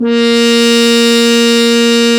Index of /90_sSampleCDs/Roland LCDP12 Solo Brass/BRS_French Horn/BRS_Mute-Stopped